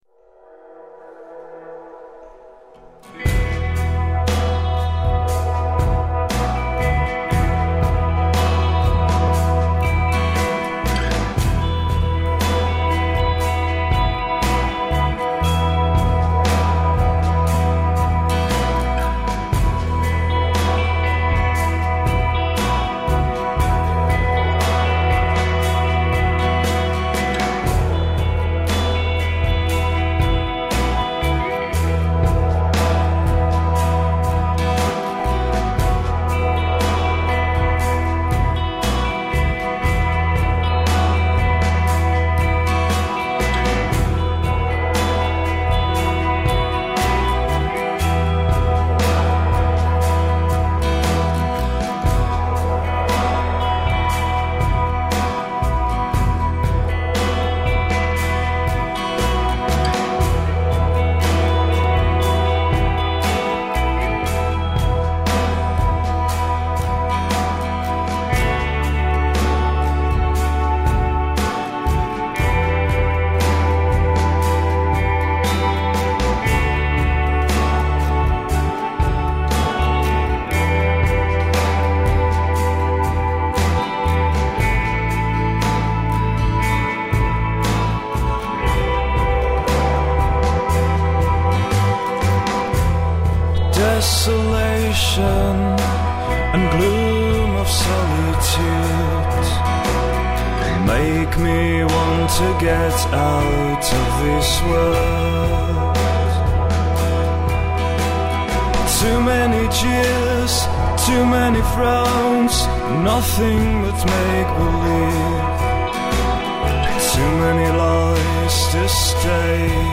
Žánr: Indie/Alternativa
Nahráno během roku 2006 v Praze a Říčanech.